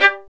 STR VLN JE0K.wav